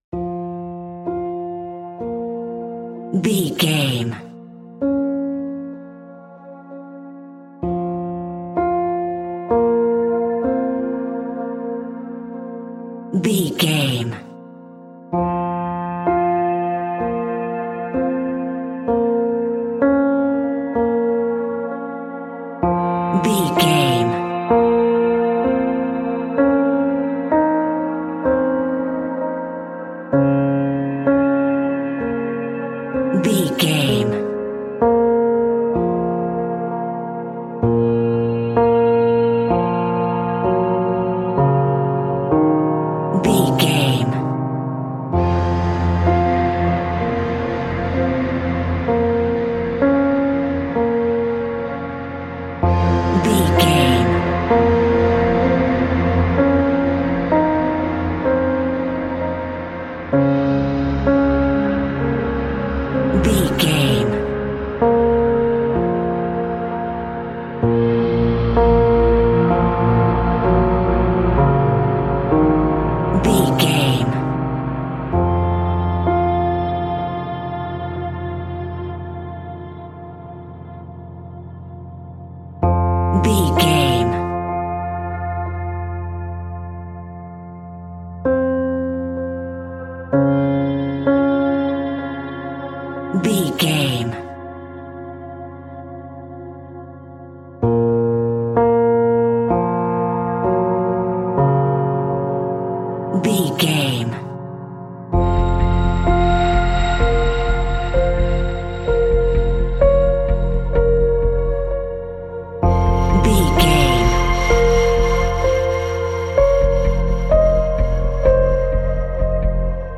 Aeolian/Minor
Slow
eerie
piano
suspenseful